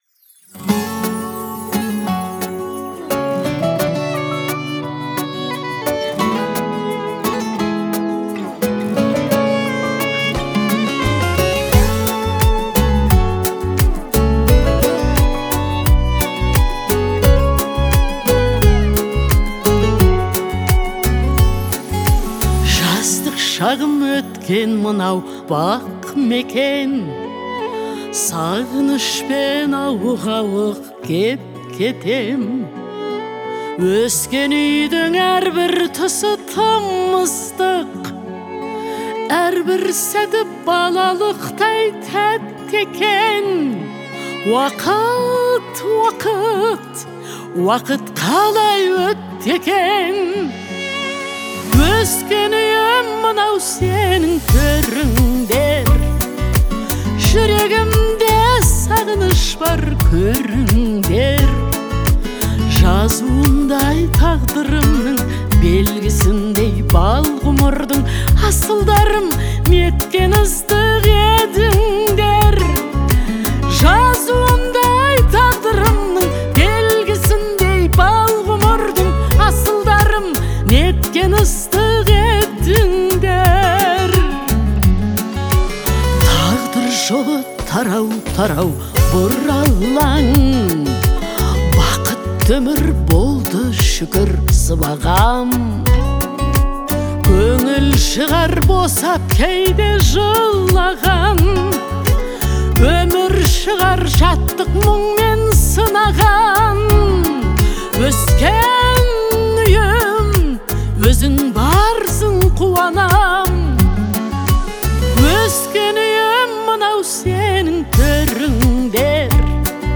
её вокал звучит мощно и выразительно.